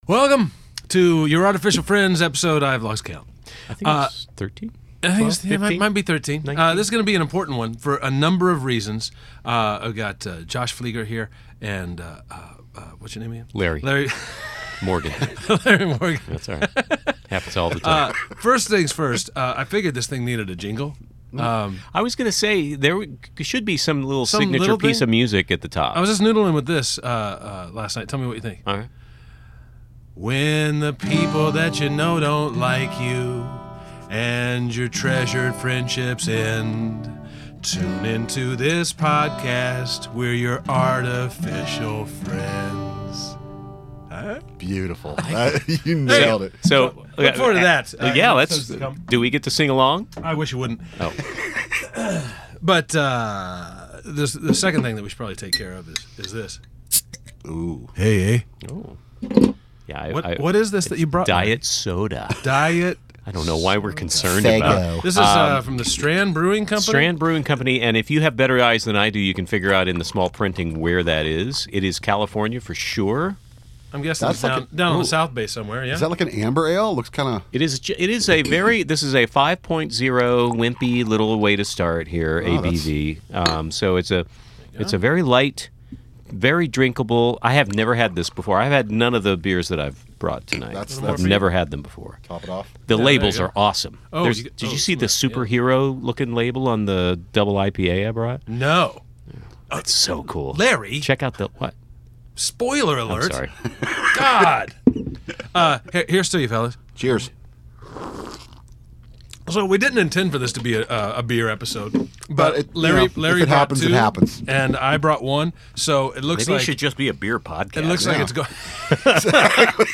While drinking beer.